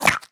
sounds / mob / fox / eat2.ogg
eat2.ogg